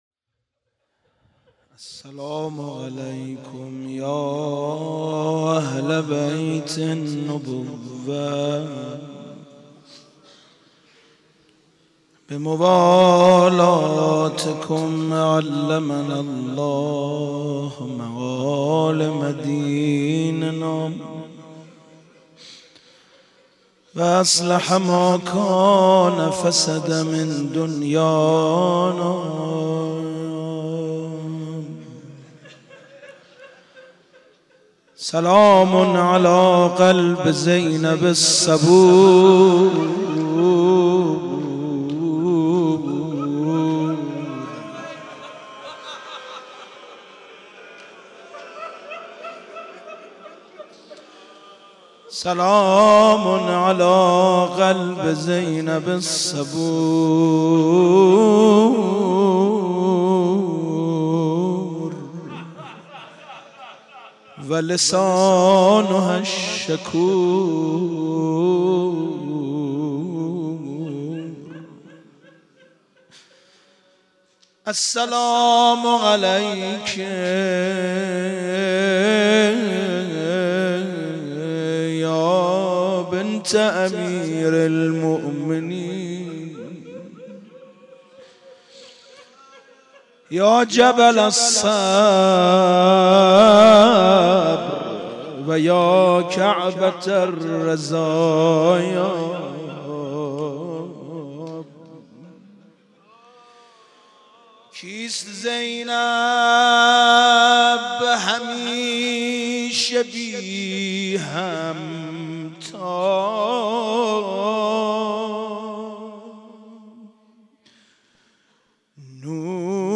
مناسبت : وفات حضرت زینب سلام‌الله‌علیها
قالب : روضه
روضه.mp3